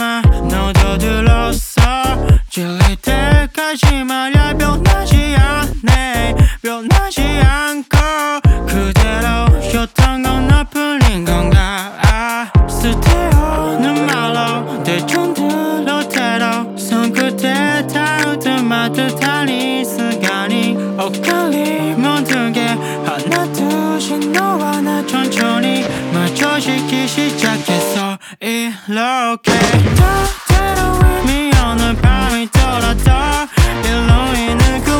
Жанр: J-pop / Поп